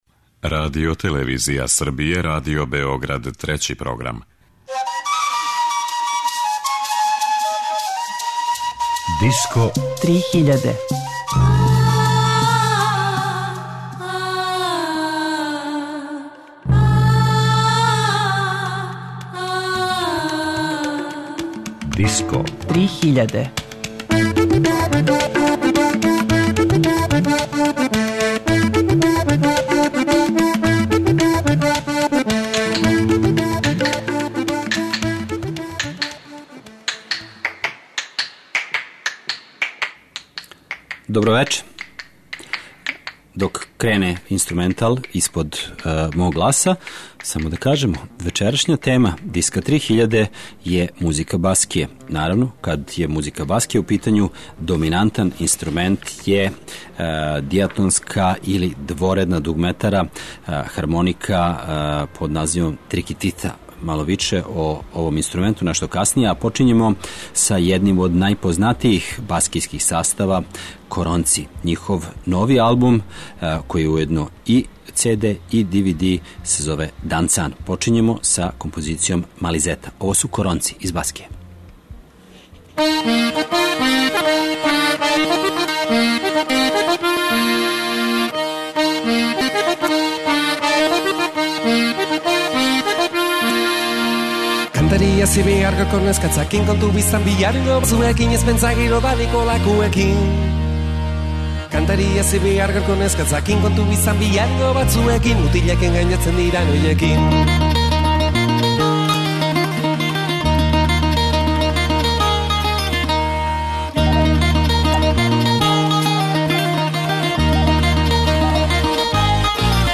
Disco 3000 је емисија посвећена world music сцени, новитетима, трендовима, фестивалима и новим албумима.